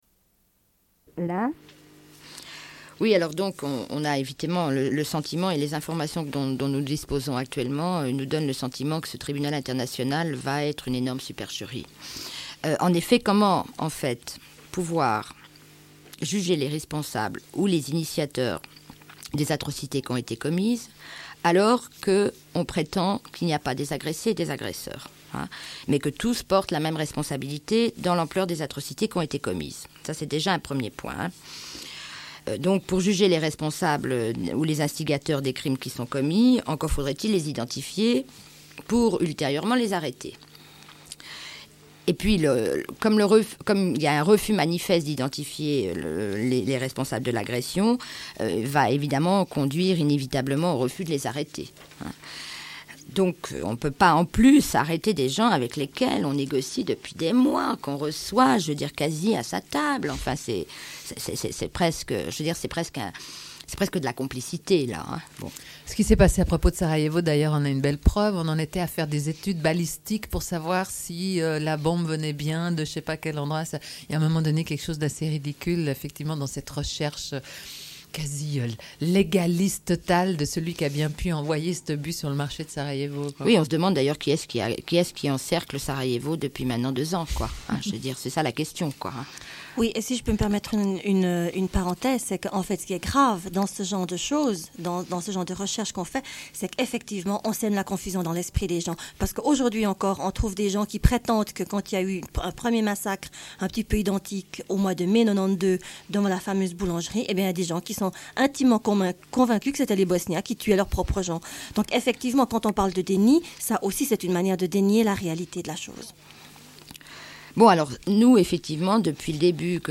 Une cassette audio, face B28:02